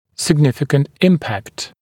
[sɪg’nɪfɪkənt ‘ɪmpækt][сиг’нификэнт ‘импэкт]значительное влияние